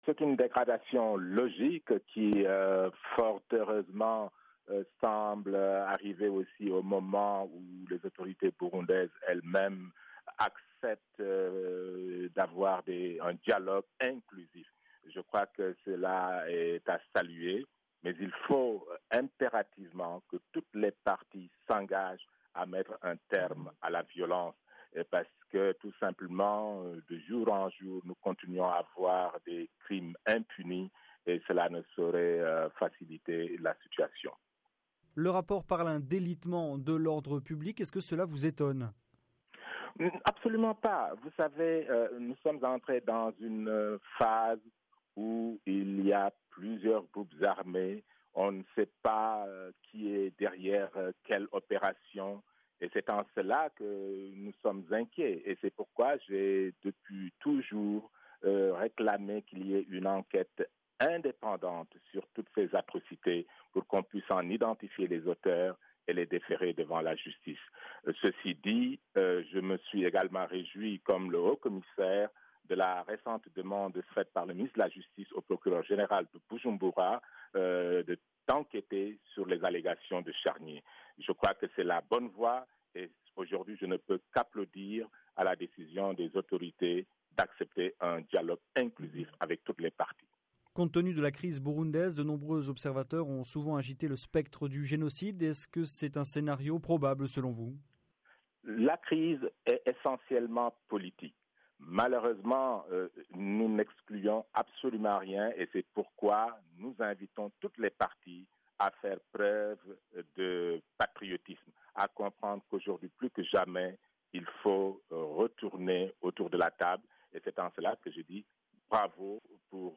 Joint par VOA Afrique, Adama Dieng conseiller spécial de l'ONU pour la prévention du génocide estime qu'il faut rapidement que le dialogue burundais reprenne pour mettre fin aux violences.